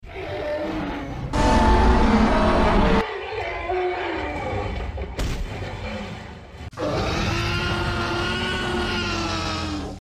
Primal Carnage Tyrannosaurus vs. Jurassic sound effects free download